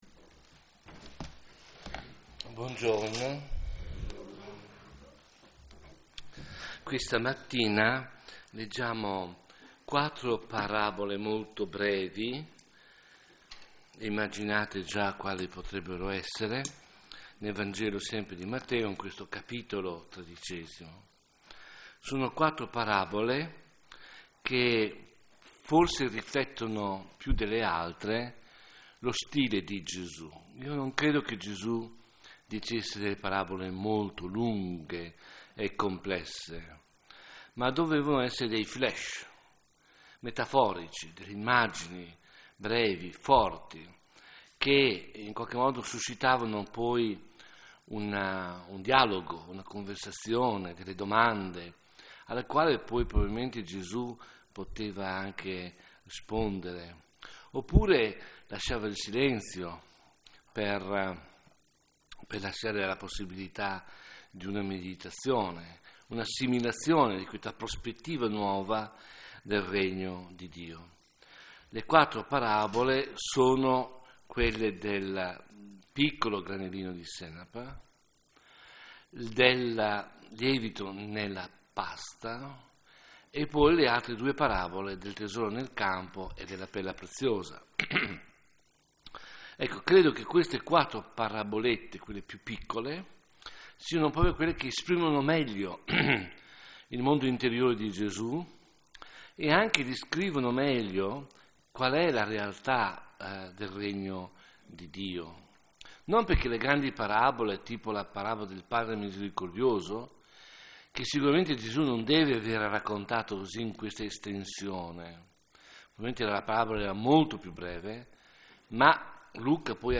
Predicazioni
Serie: Meditazione